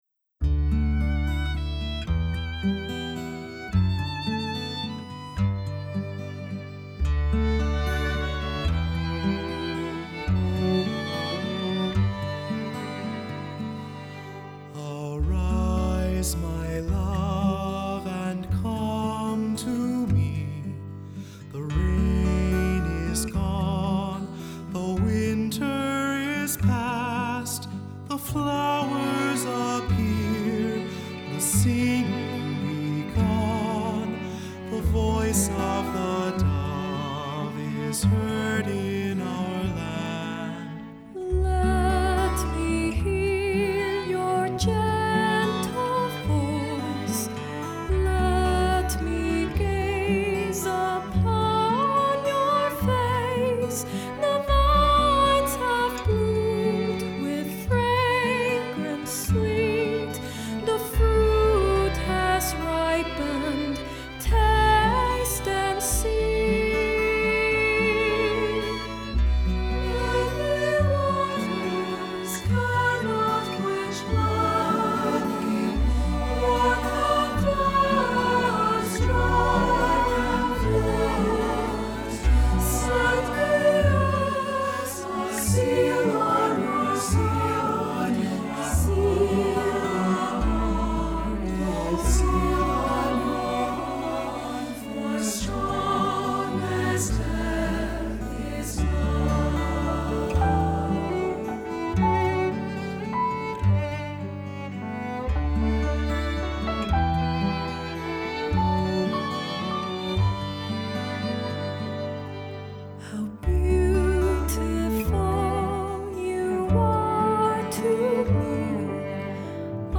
Voicing: SAT; Solos; Assembly